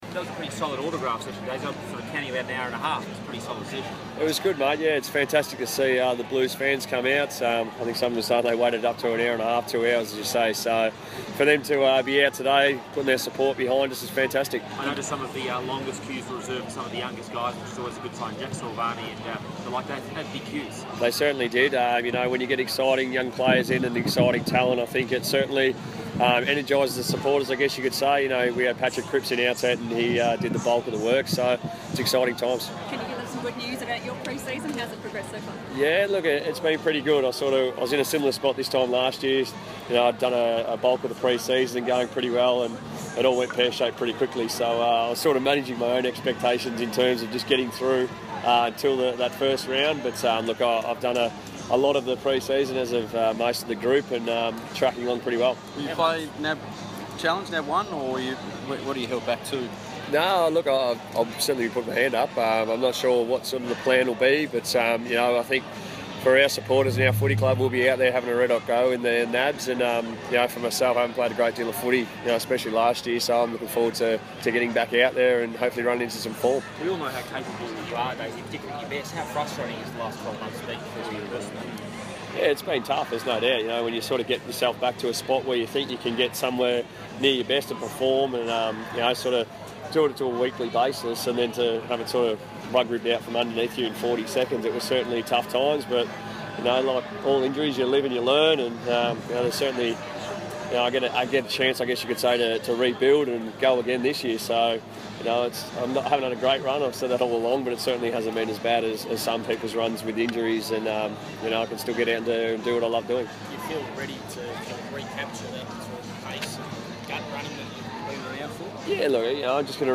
Dale Thomas press conference - February 7
Midfielder Dale Thomas chats to the media at Carlton's 2016 Family Day, held at Ikon Park.